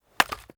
Free Fantasy SFX Pack
Chopping and Mining
chop 1.wav